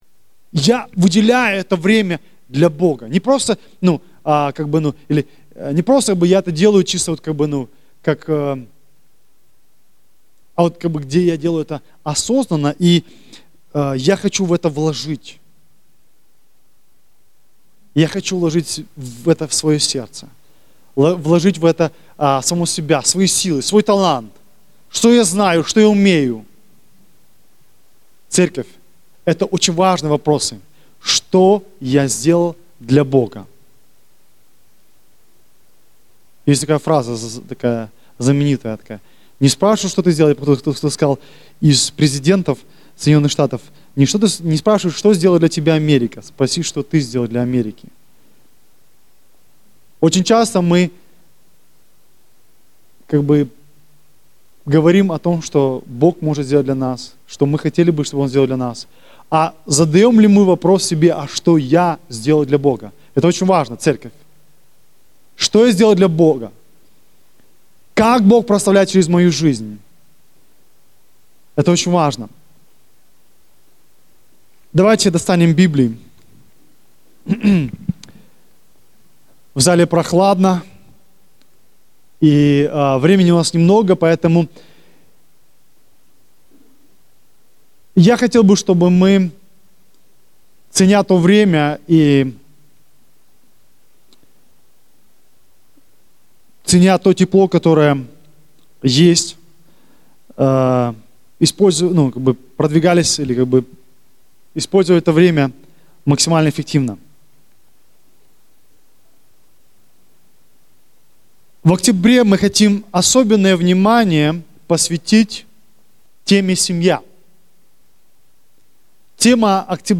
Предлагаем вам скачать первую проповедь данной тематики.